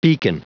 Prononciation du mot beacon en anglais (fichier audio)
Prononciation du mot : beacon